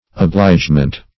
Search Result for " obligement" : The Collaborative International Dictionary of English v.0.48: obligement \o*blige"ment\ ([-o]*bl[imac]j"ment), n. Obligation.